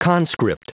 Prononciation du mot conscript en anglais (fichier audio)
Prononciation du mot : conscript